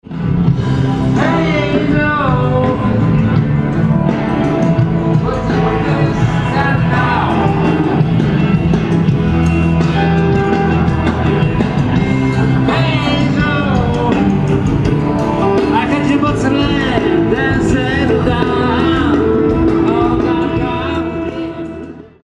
Na wrocławskim rynku wybrzmiało 7967 gitar!